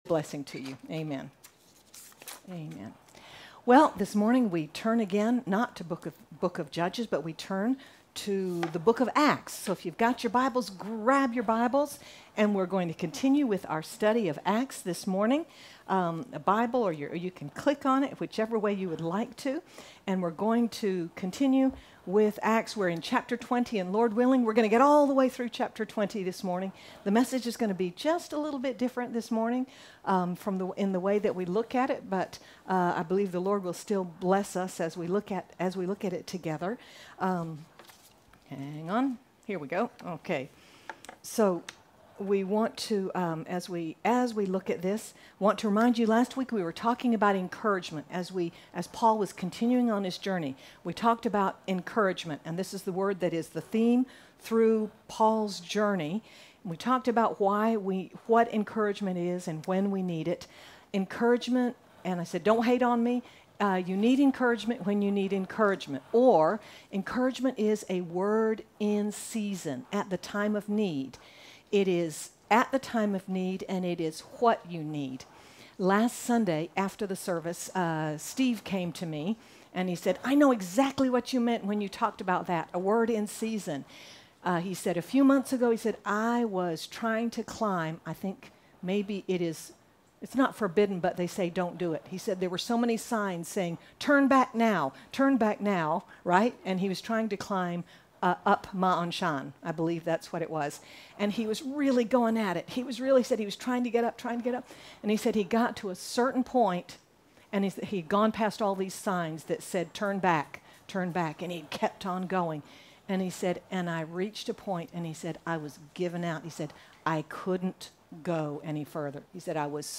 May 17, 2022 Finances, a Fall, and Farewell MP3 SUBSCRIBE on iTunes(Podcast) Notes Discussion As Paul begins his homeward journey, we are reminded how to handle money, to keep our focus on what matters most, and to consider the mark we leave on others. Sermon by